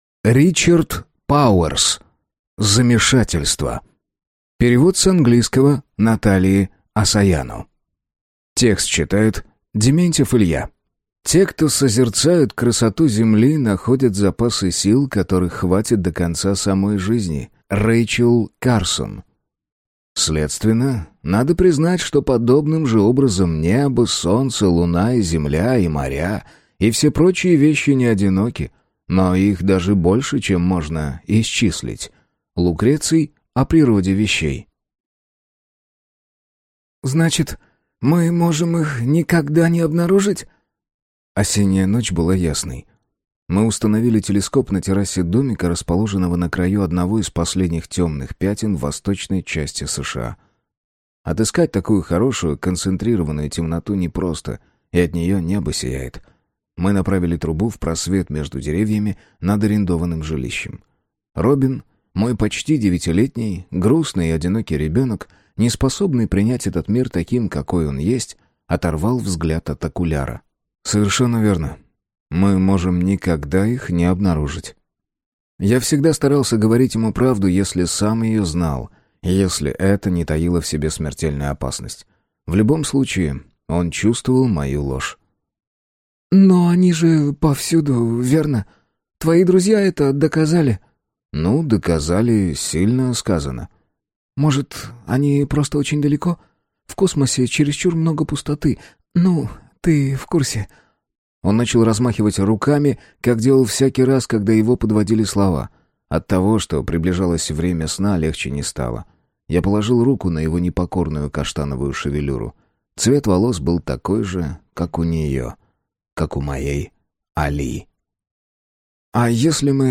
Аудиокнига Замешательство | Библиотека аудиокниг